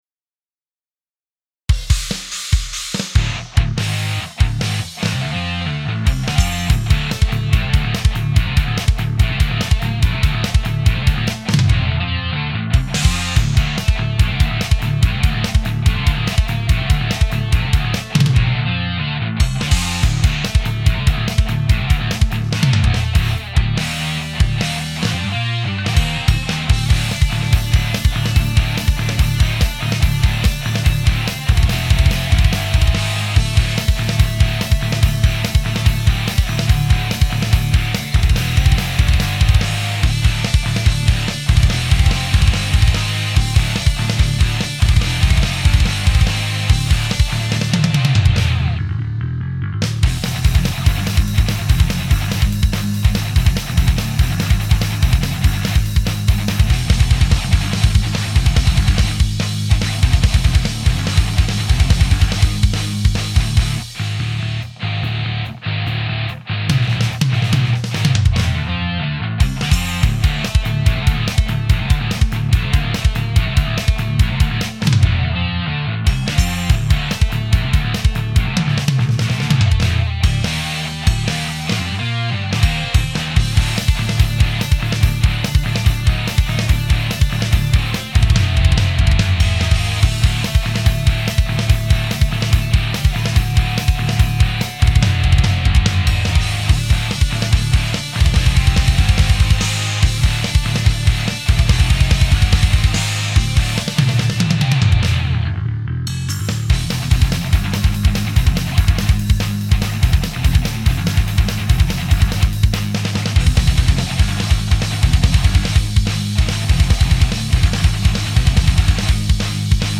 Hard Rock Song: Sunset Country Disco
hey all , looking for some feedback on the mix.